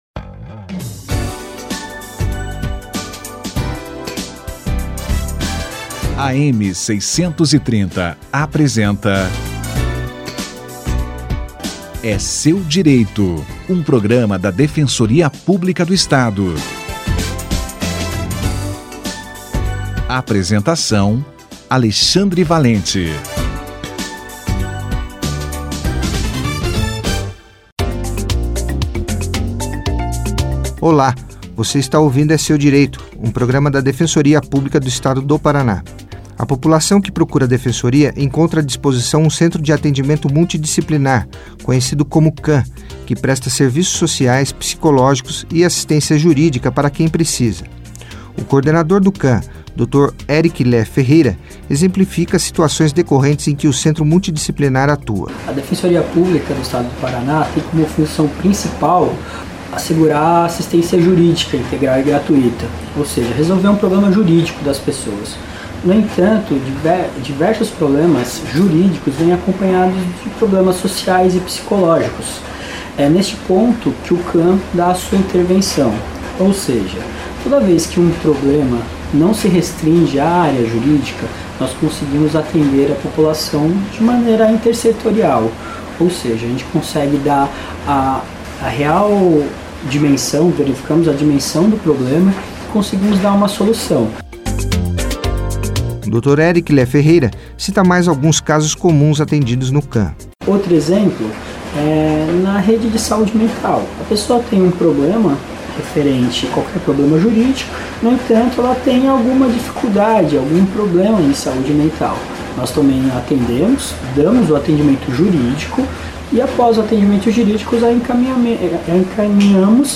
29/10/2018 - Centro de Atendimento Multidisciplinar (CAM) no atendimento à população. Entrevista